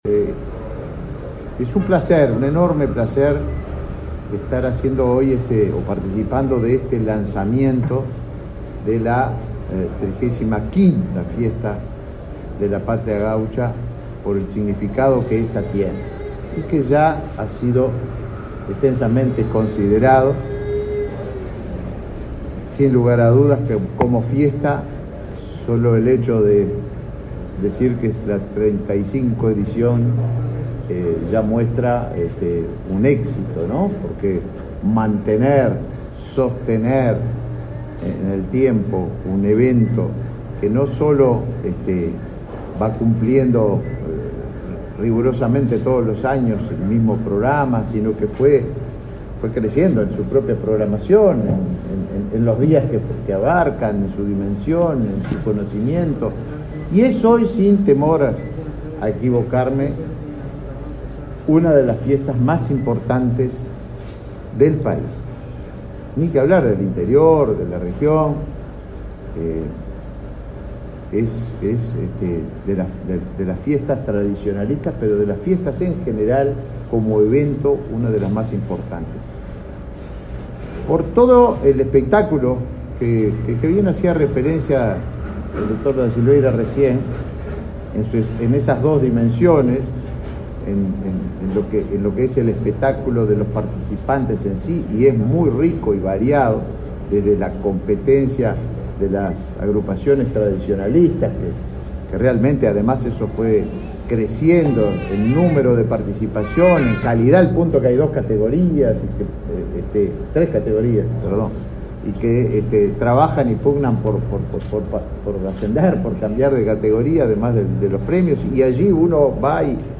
Palabras del ministro de Turismo
El ministro de Turismo, Tabaré Viera, participó este miércoles 9 en el lanzamiento de la 35.ª edición de la Fiesta de la Patria Gaucha.